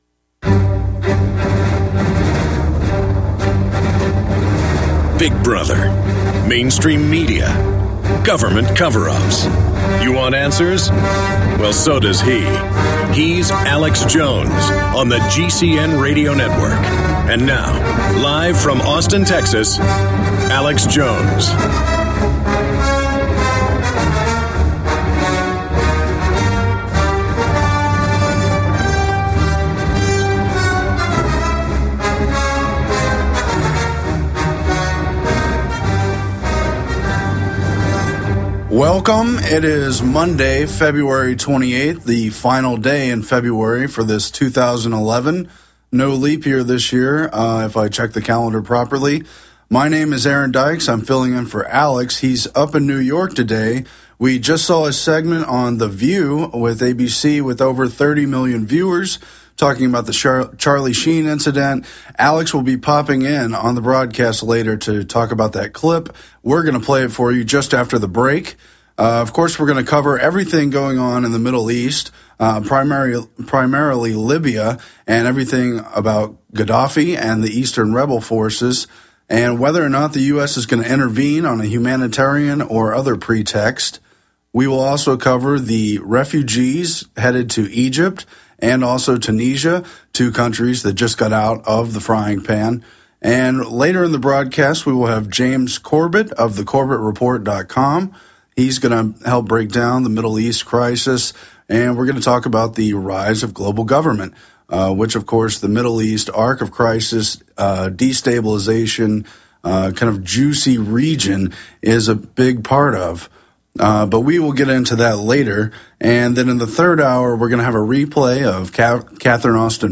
Alex Jones Show Commercial Free Podcast
Watch Alex's live TV/Radio broadcast.